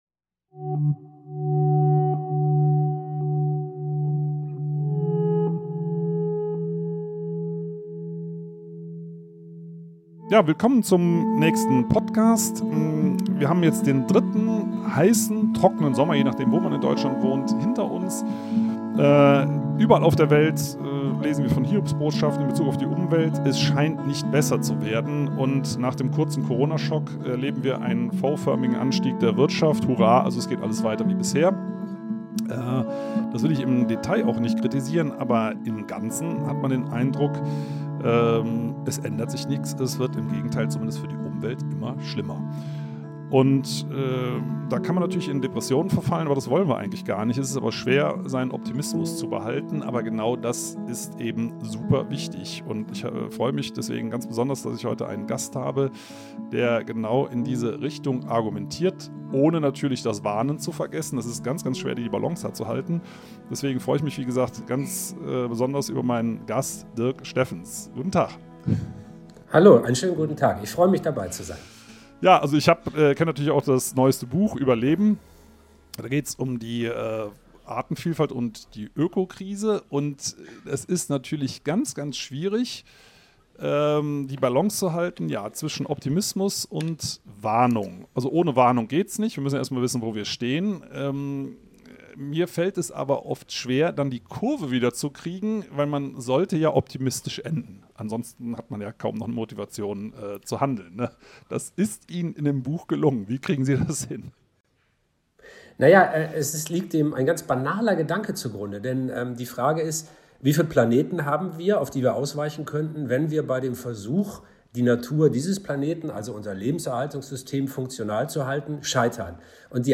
Heute ist der Journalist und Terra X Moderator Dirk Steffens zu Gast. Wir reden über Artenschutz, Biodiversität und Optimismus im Umweltschutz.